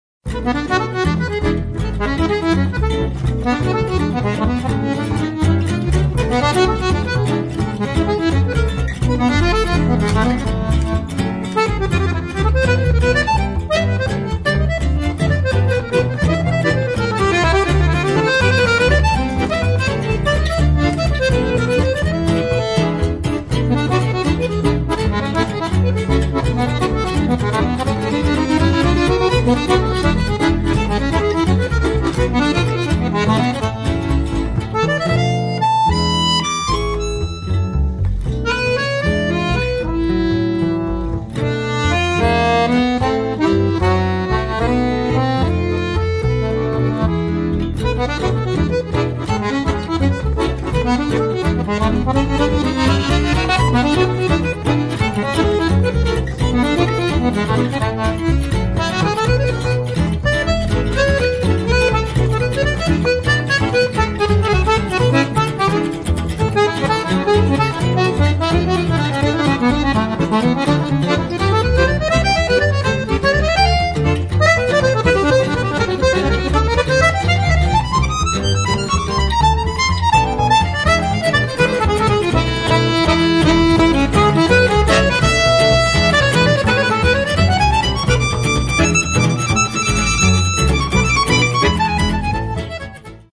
– Un quartet de jazz de plus !
– Yes, but there’s an accordion.
– And a double bass.
– And tender.
– No, it’s quite lively.